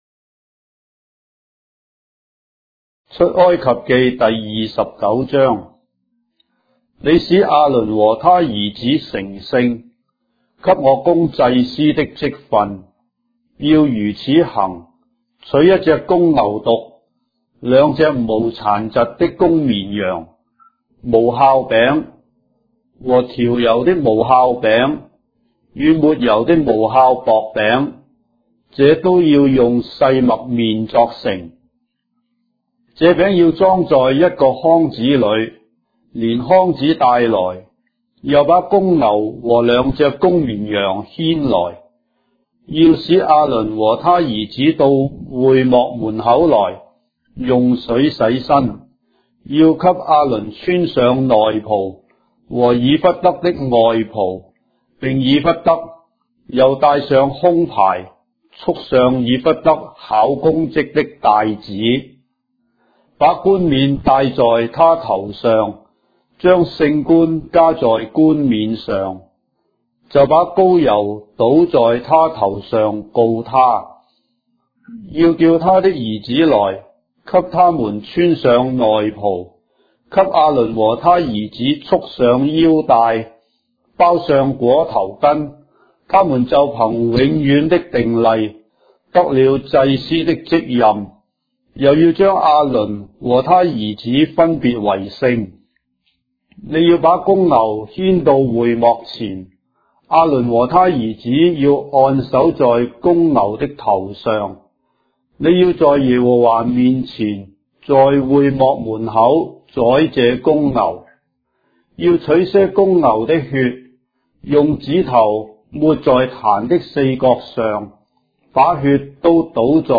章的聖經在中國的語言，音頻旁白- Exodus, chapter 29 of the Holy Bible in Traditional Chinese